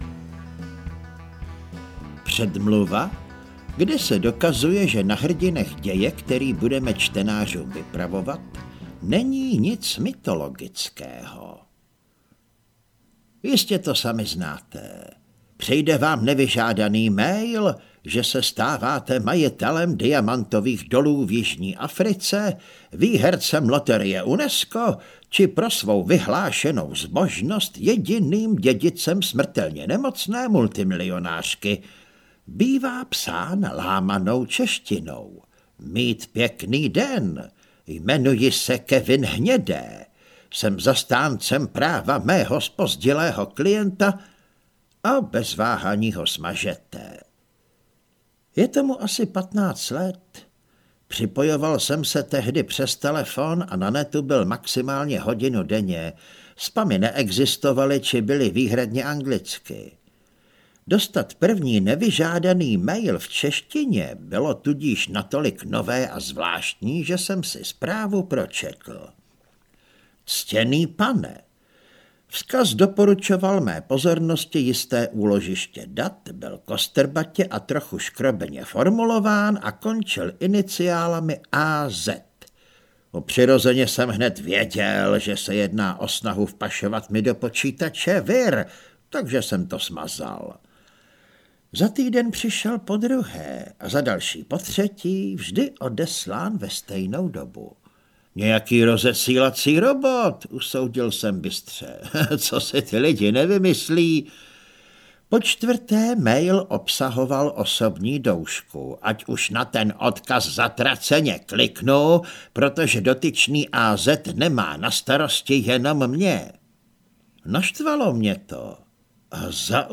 Tři kapitáni 1 - Mračna nad arénou audiokniha
Ukázka z knihy